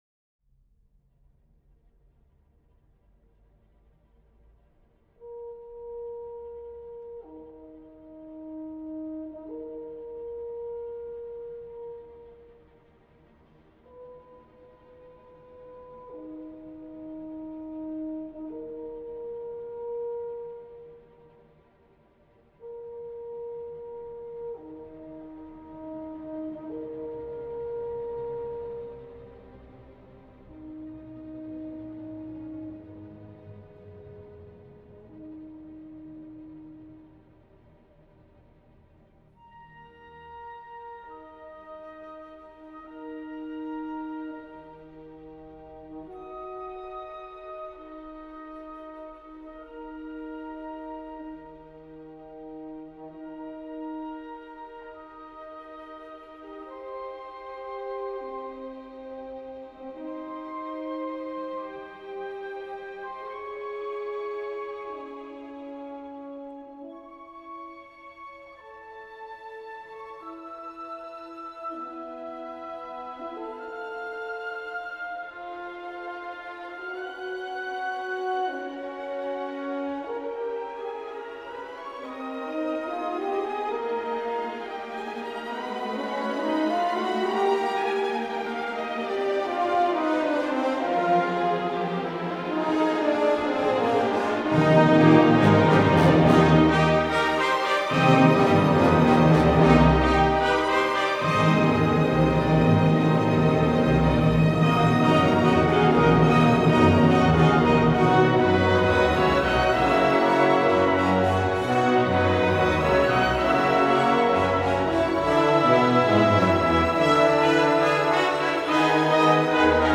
The performance took place at the Saint Ferdinand Church in Laval, Quebec on June 18, 1996.
1.-Bewegt_nicht-zu-schnell.flac